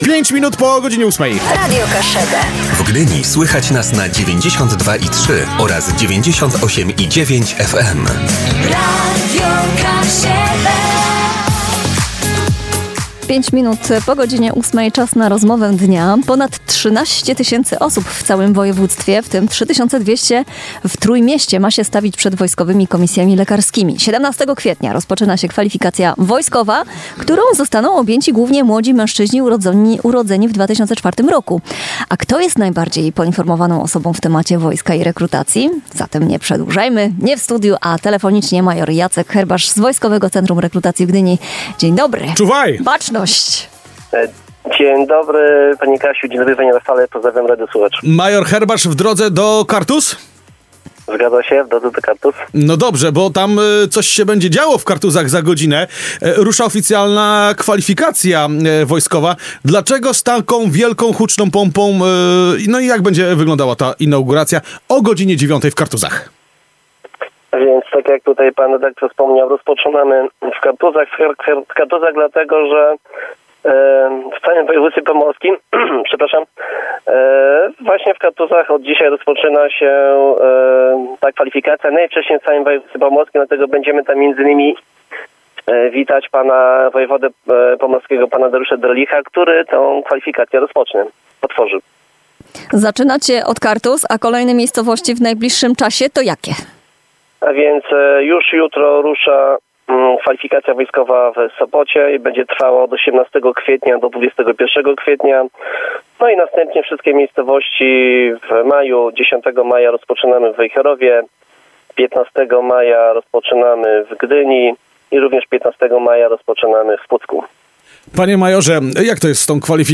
Zatem nie przedłużajmy, nie w studiu a telefonicznie